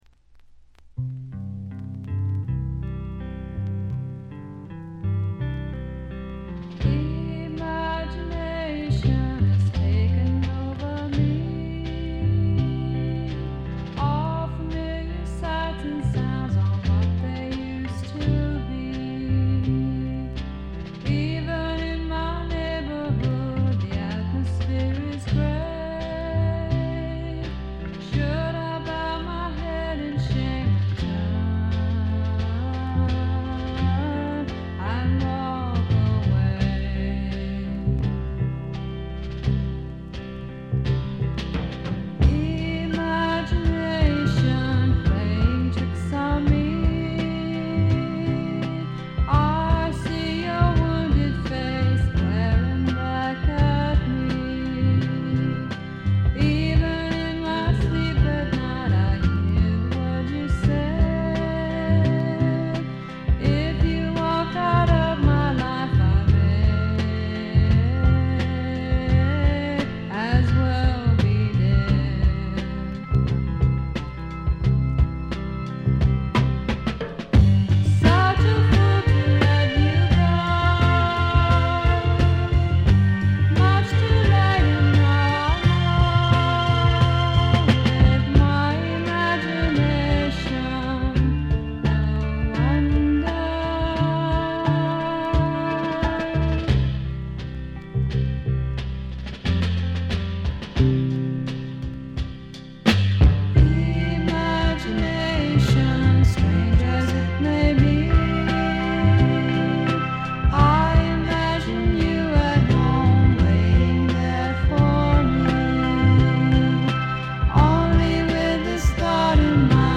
軽微なバックグラウンドノイズ、チリプチ程度。
ソフト・ロック、ソフト・サイケ、ドリーミ・サイケといったあたりの言わずと知れた名盤です。
試聴曲は現品からの取り込み音源です。